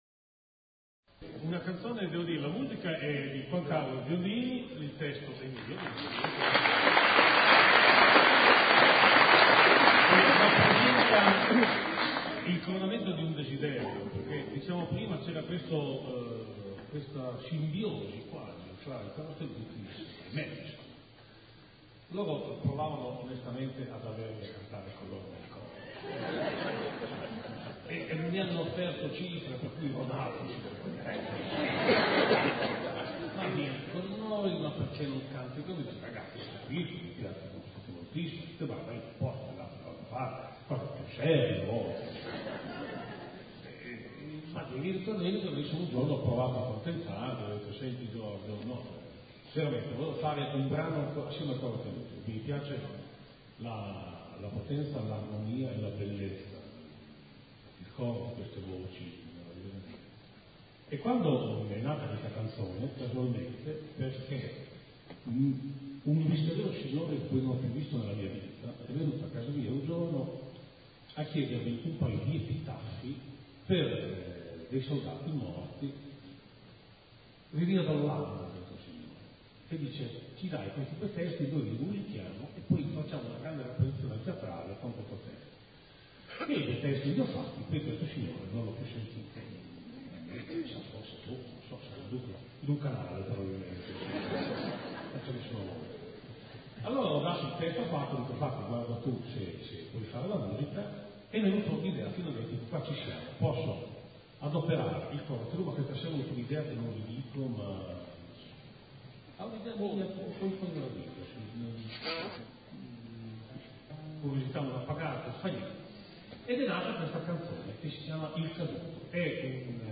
[ voci miste ]